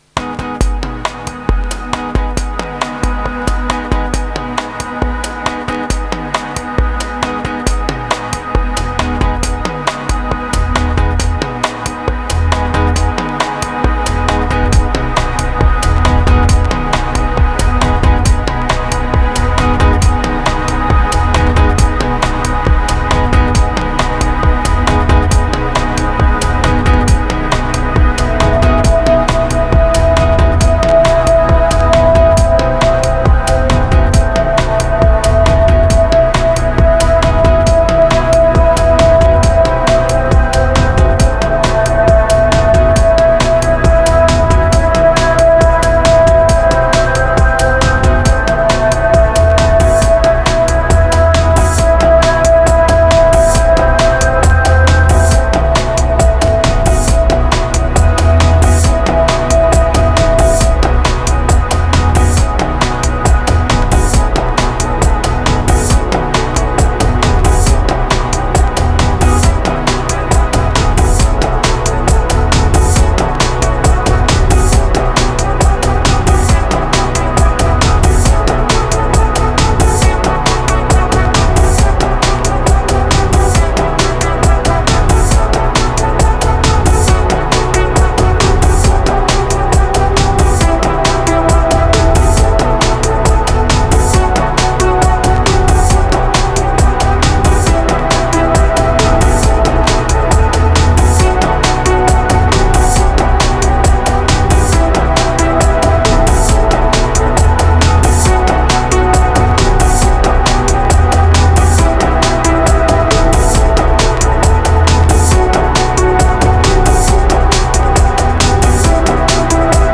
962📈 - 83%🤔 - 68BPM🔊 - 2025-08-05📅 - 687🌟
The main sample is AI generated.
Prog and static, I had doubts about this track.
Electro Progressive Bass Sparse Fetch Relief Moods Modal